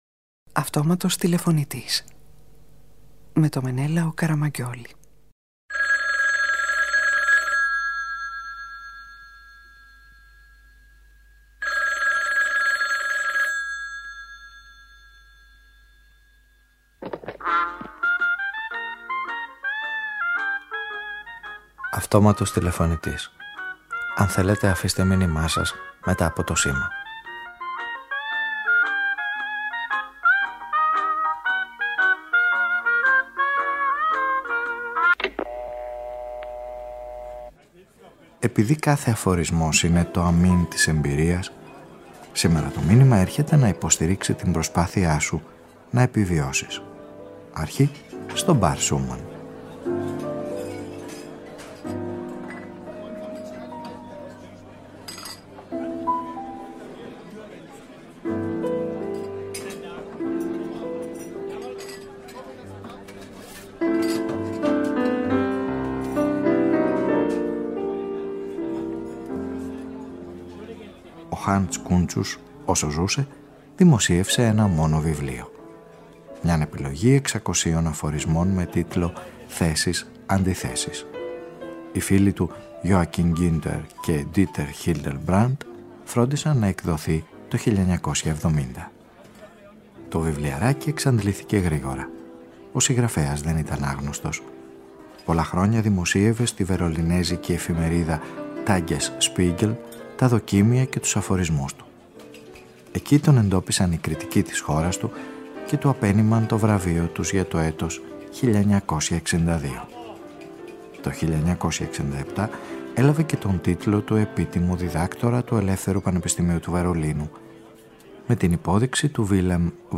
Μια ραδιοφωνική ιστορία που ξεκινάει με την υπόθεση πως η μελαγχολία είναι η ευτυχία όσων δυσκολεύονται και με τη βοήθεια του Χανς Κούντσους ψάχνει στο αμήν κάθε εμπειρίας τον αφορισμό που θα σε βοηθήσει κι εσένα όπως κι εκείνον να επιβιώσεις.